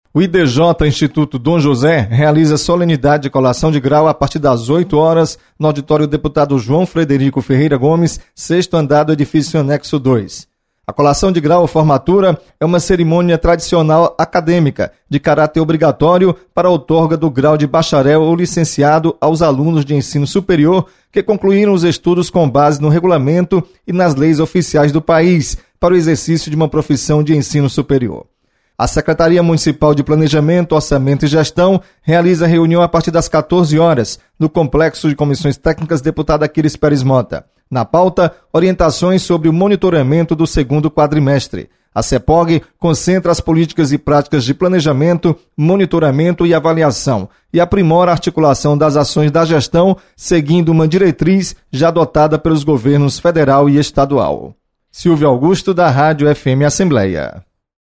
Acompanhe as atividades desta quarta-feira na Assembleia Legislativa. Repórter